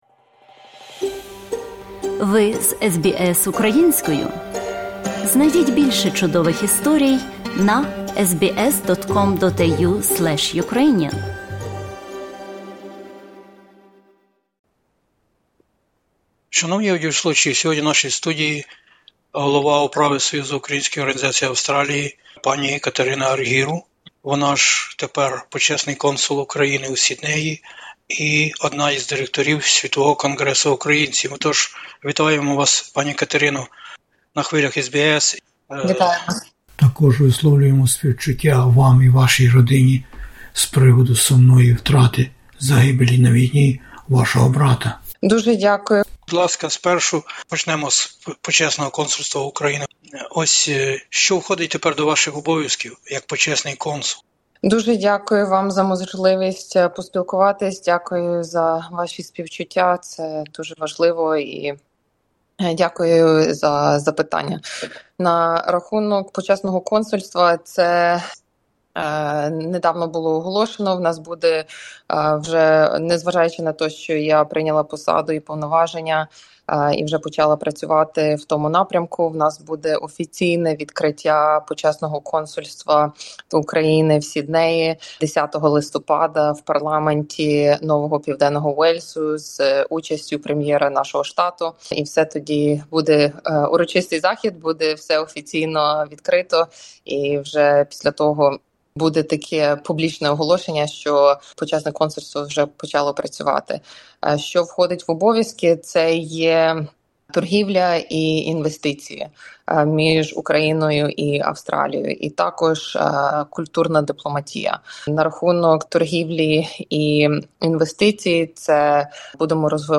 У розмові з SBS Ukrainian високодостойна п-і Катерина Арґіру, Голова Управи Союзу Українських Організацій Австралії, Почесний консул України у Сіднеї та членкиня Ради директорів Світового Конґресу Українців, розповідає про дні насущні української діаспори та її прагнення допомогти Україні відстояти незалежність у війні, що триває від 2014-го року на українських землях...